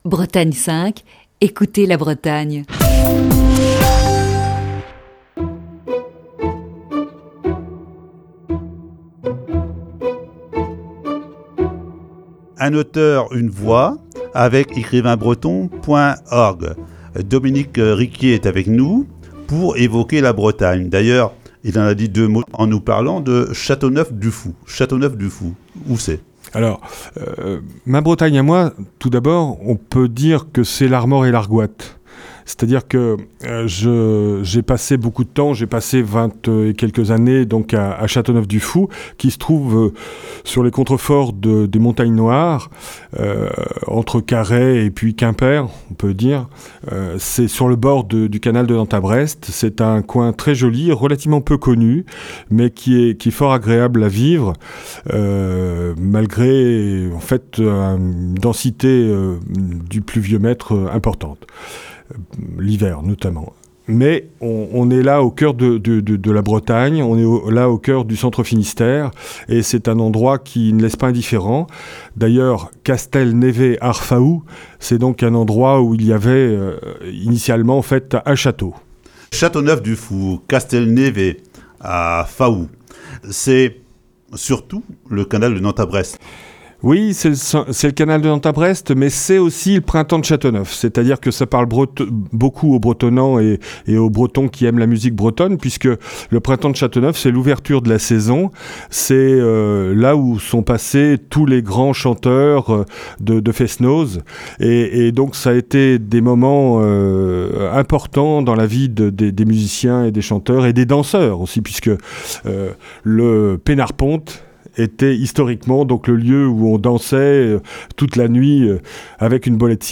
Ce matin, voici la deuxième partie de cet entretien diffusé le 3 décembre 2019.